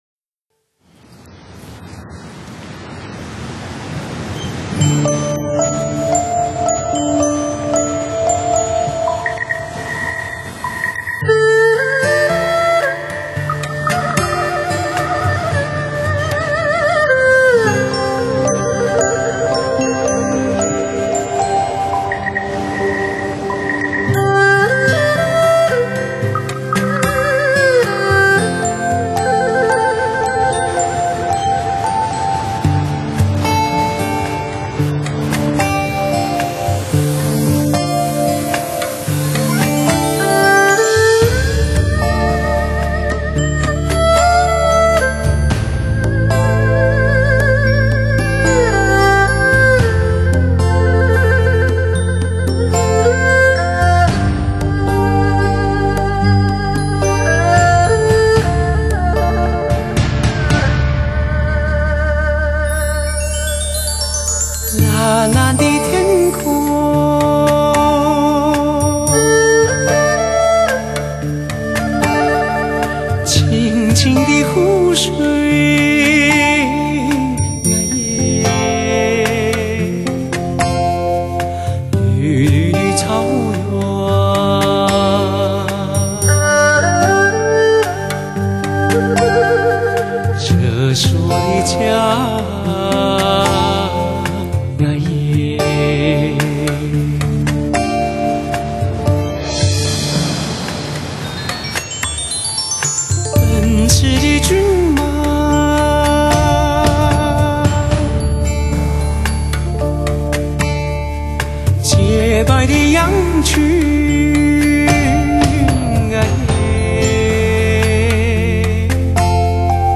音质方面拥有无与伦比的通透，各类音色对比鲜明，
乐器演奏活灵活现，靓绝深情的旋律，音效极致HI-FI，